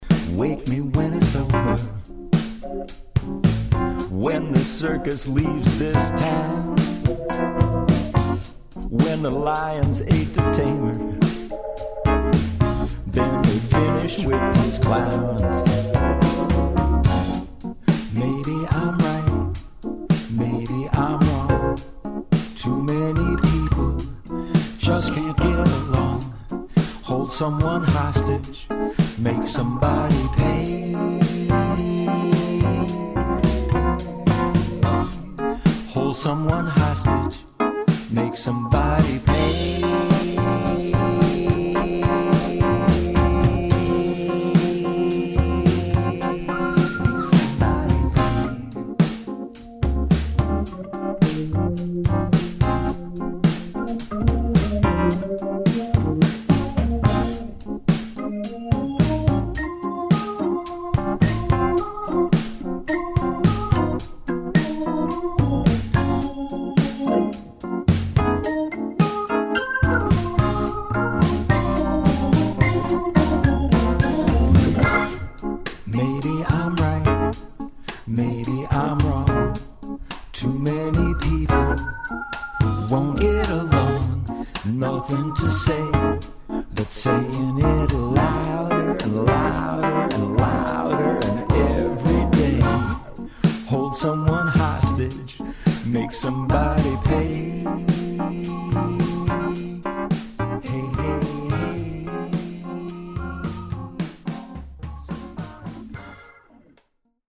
The doctor is in lockdown but still groovin’!
through the miracle of the telephone–
to lasso some space cowboy changes
into a wild new cadence–